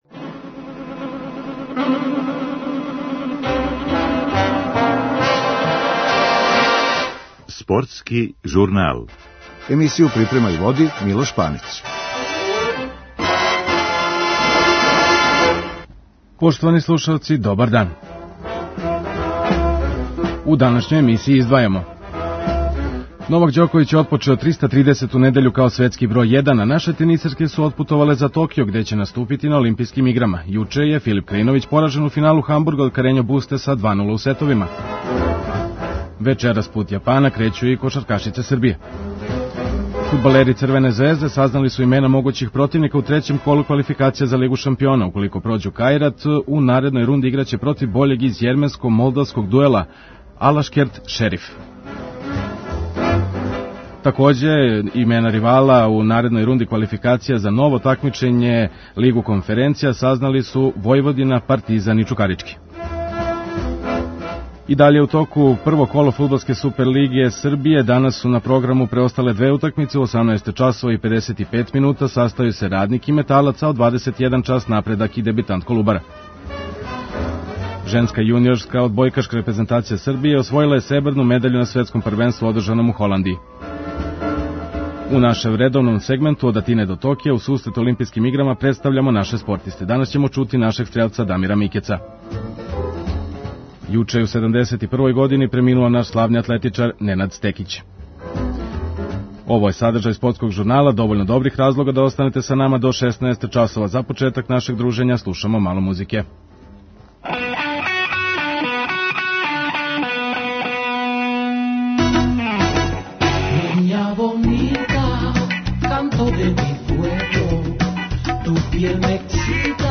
У нашем редовном сегменту ОД АТИНЕ ДО ТОКИЈА у сусрет Олимпијским играма, представљамо наше спортисте.